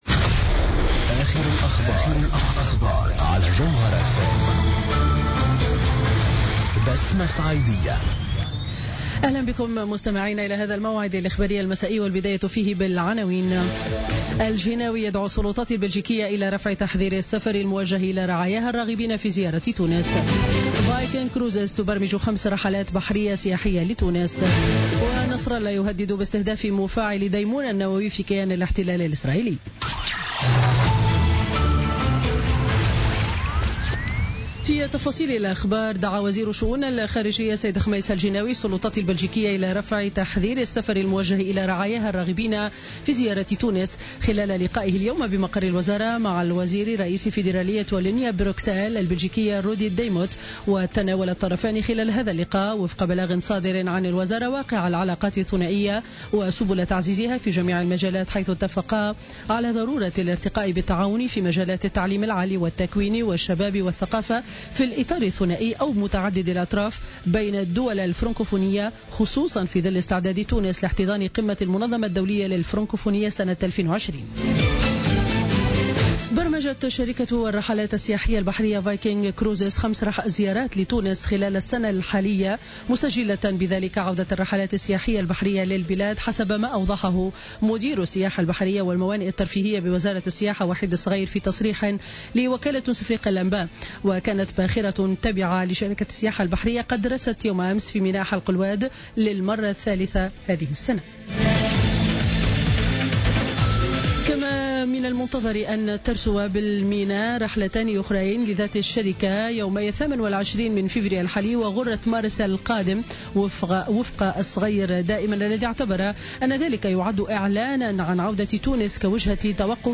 نشرة أخبار السابعة مساء ليوم الخميس 16 فيفري 2017